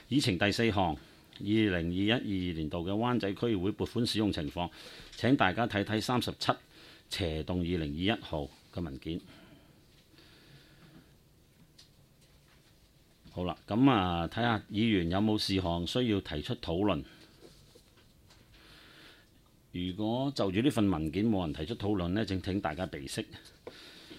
区议会大会的录音记录
湾仔区议会第十四次会议
湾仔民政事务处区议会会议室